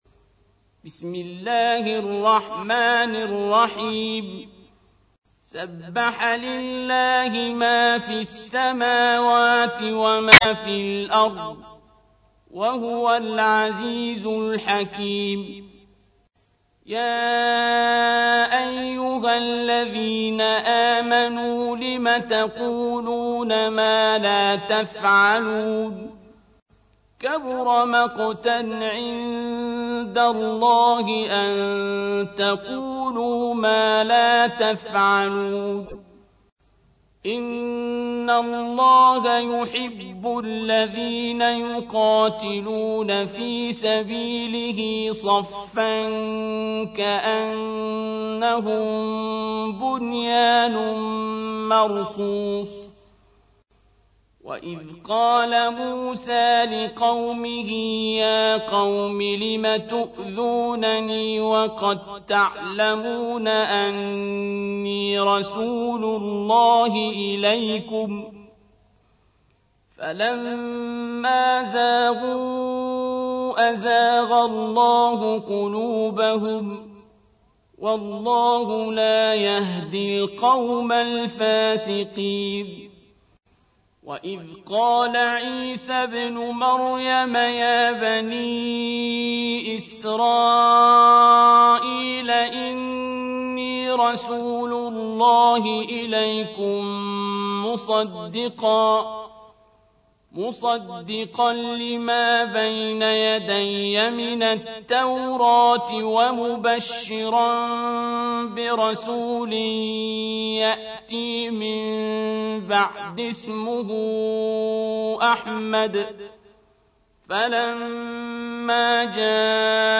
تحميل : 61. سورة الصف / القارئ عبد الباسط عبد الصمد / القرآن الكريم / موقع يا حسين